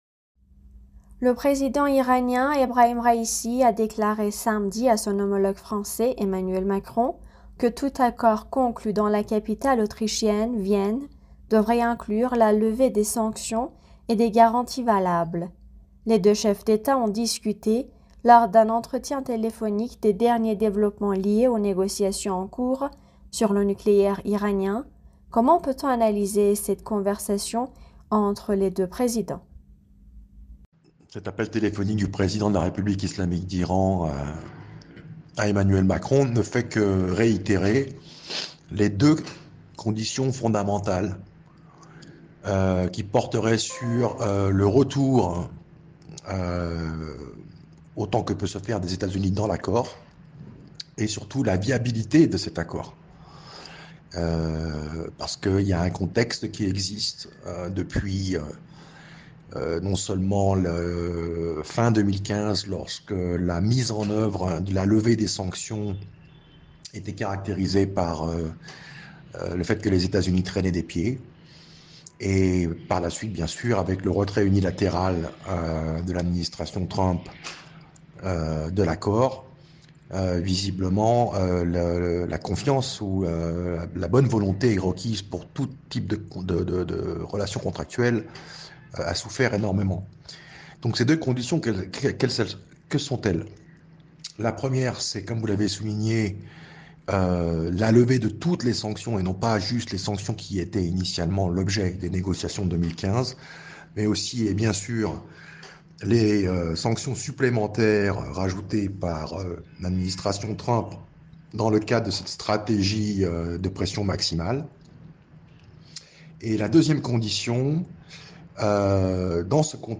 Mots clés Iran France Raïssi interview Eléments connexes Quelles villes iraniennes sont reconnues au patrimoine mondial pour leur artisanat ?